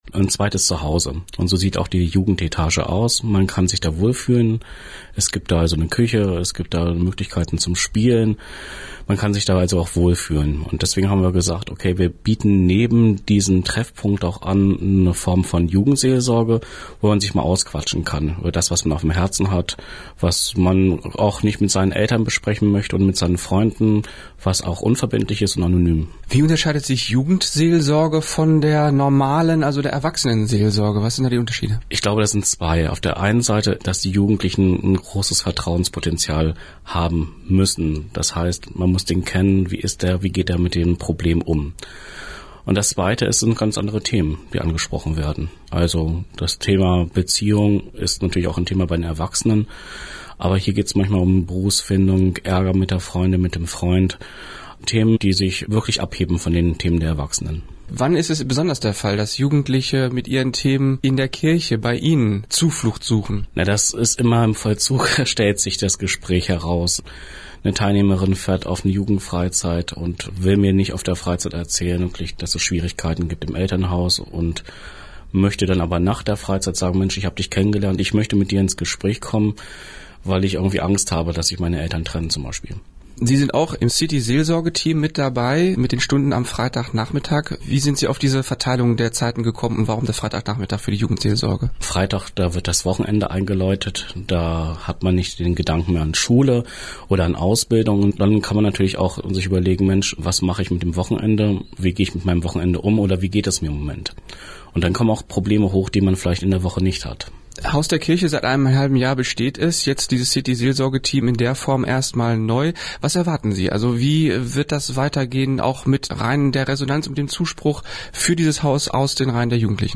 Wir danken Radio Aktiv für die Überlassung dieses Interviews.